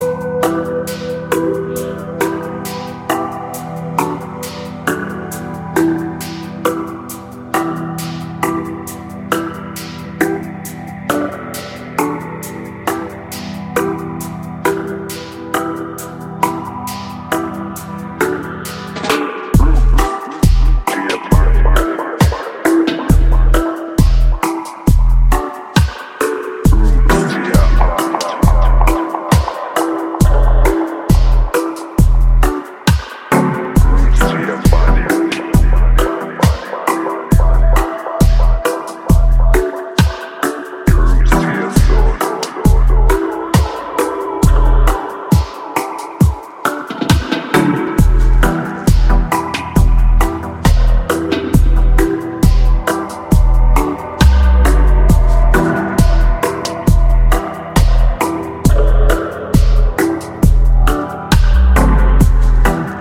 シロップのように甘くヘヴィなローエンド、郷愁に満ちたメロディカ、精霊のように漂うヴォーカル